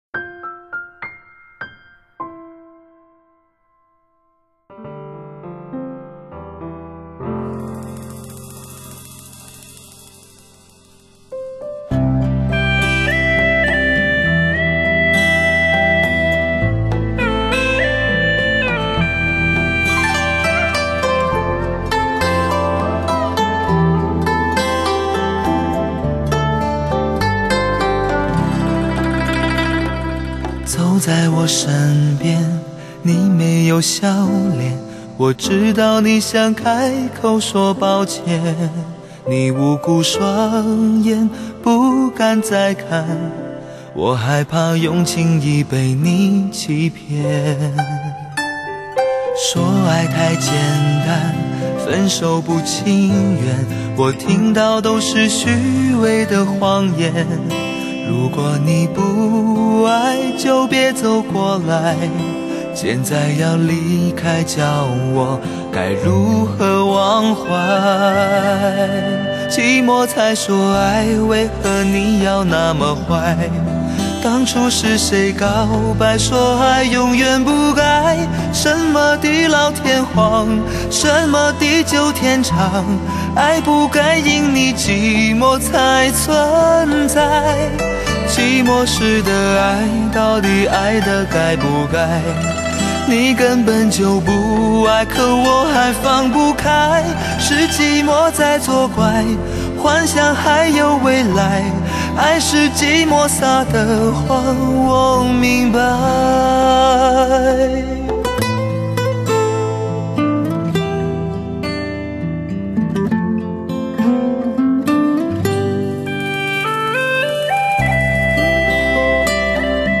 心灵无法抗拒的性感男声
深情动容  真情的呐喊  沙哑的倾诉  绝对震撼每一位聆听者的心弦